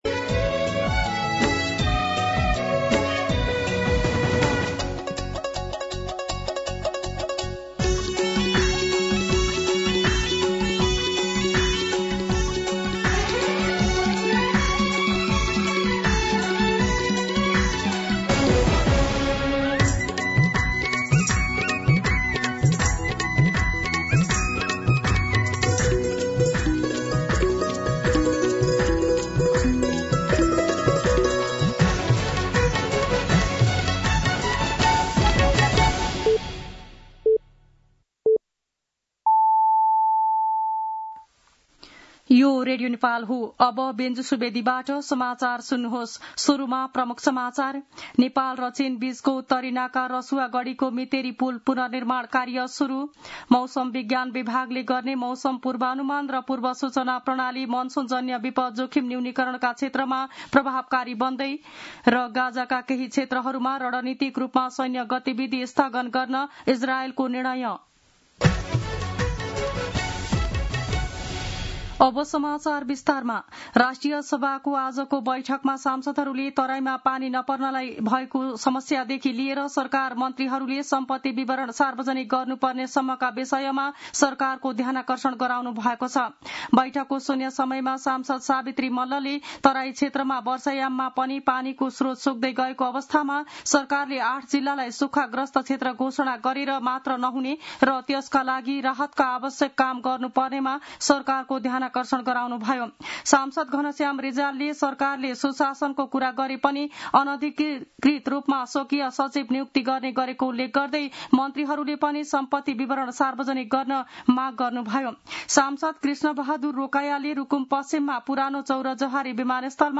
दिउँसो ३ बजेको नेपाली समाचार : ११ साउन , २०८२
3-pm-Nepali-News-4.mp3